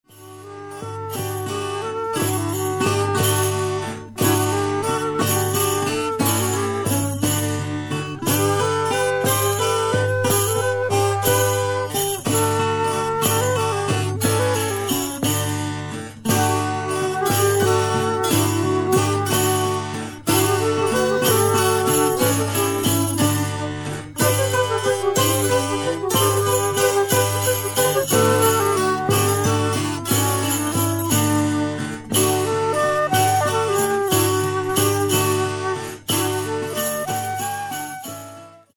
BLUES ROCK / COUNTRY BLUES / SSW